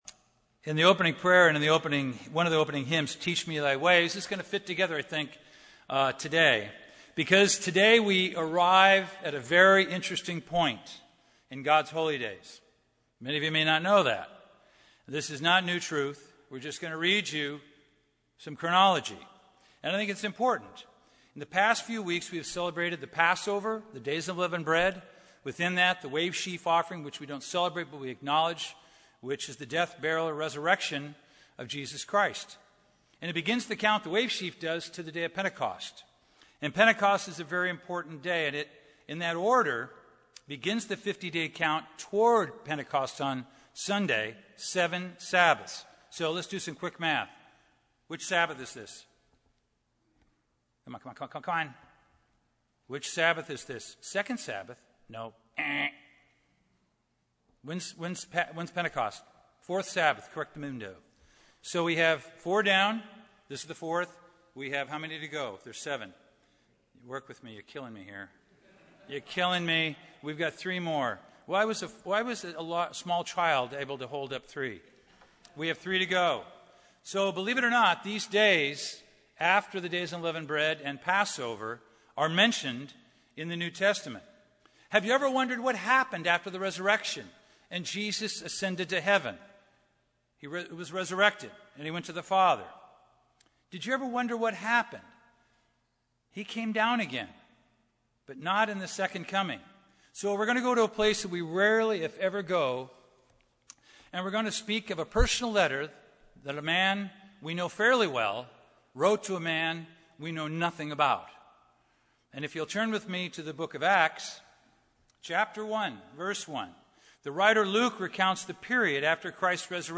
Sermons
Given in Los Angeles, CA Redlands, CA